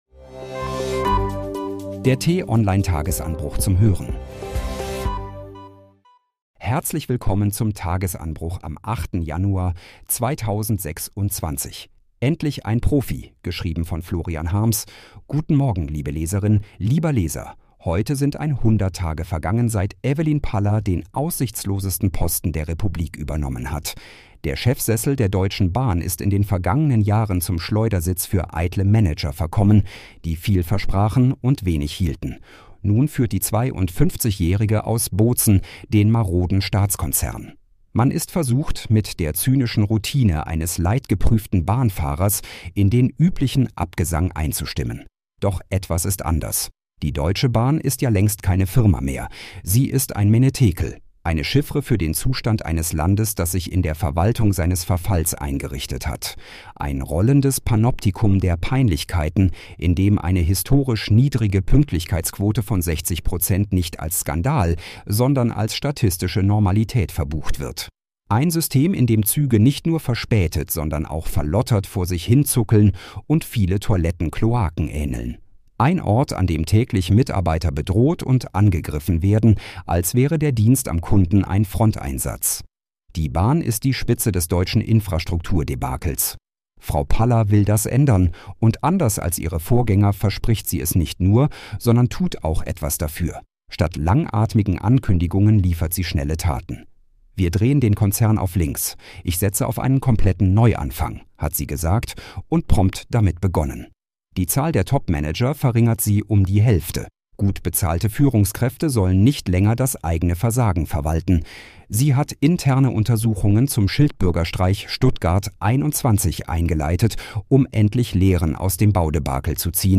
zum Start in den Tag vorgelesen von einer freundlichen KI-Stimme –